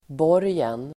Uttal: [b'år:jen]
borgen.mp3